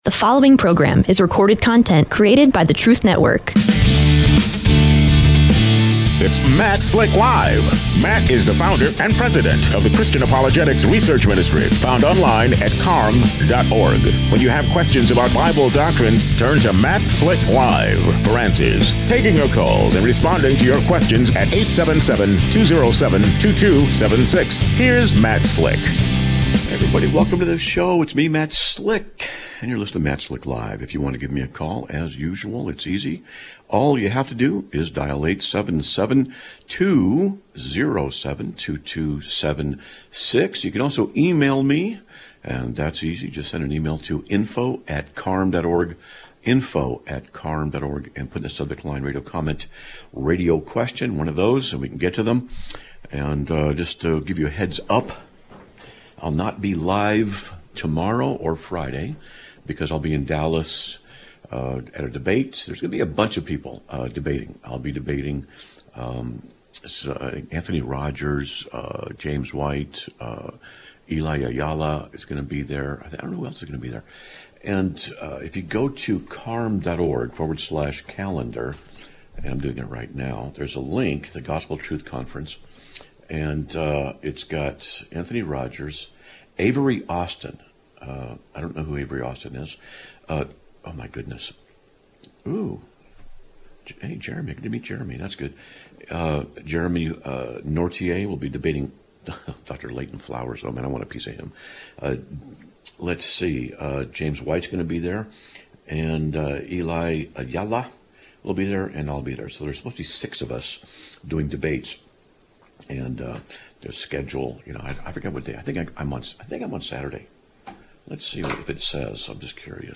Live Broadcast of 02/04/2026